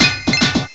cry_not_bronzor.aif